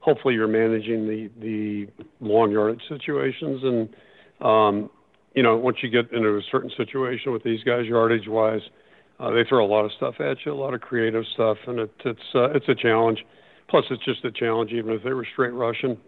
That’s Iowa coach Kirk Ferentz who says the Hawkeyes need to avoid third and long.